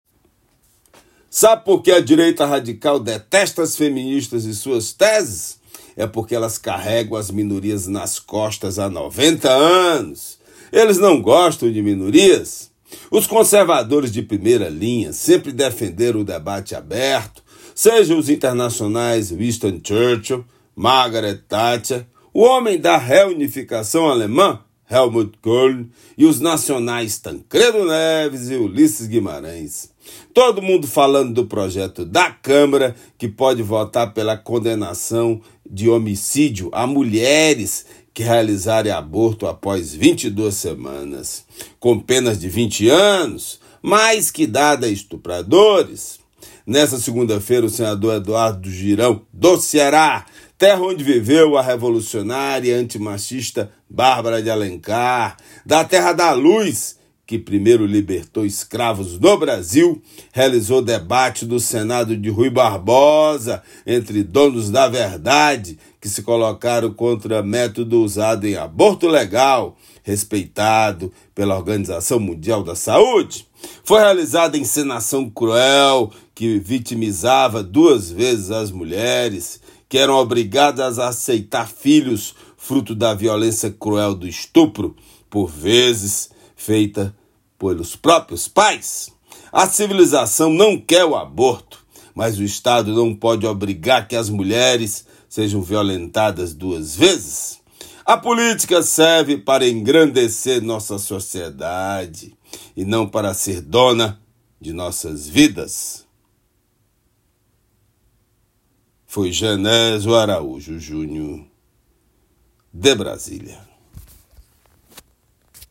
Comentário desta terça-feira (18/06/24)
direto de Brasília.